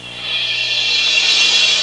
Cymbal Intro Sound Effect
Download a high-quality cymbal intro sound effect.
cymbal-intro.mp3